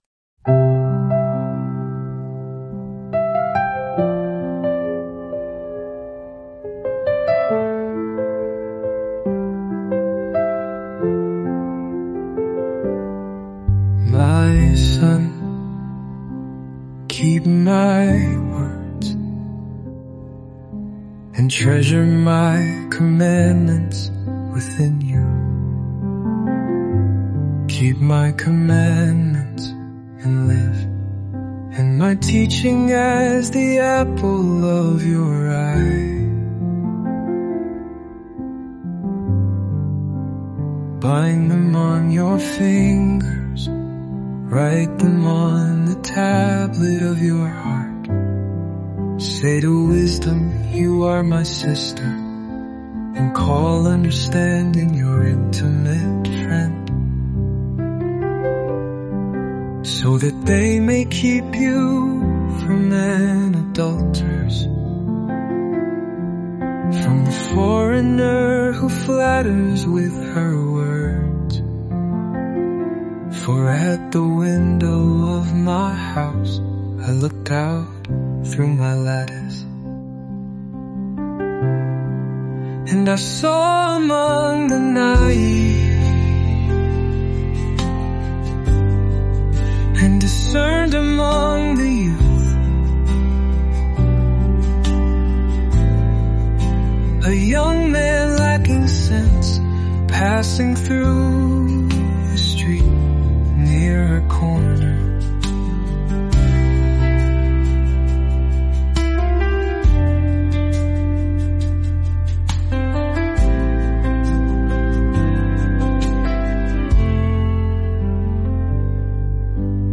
Immerse yourself in the timeless wisdom of Proverbs in just 31 days through word-for-word Scripture songs. Each day, listen to passages that offer guidance for daily life—covering wisdom, integrity, relationships, work, and the fear of the Lord—brought to life through music.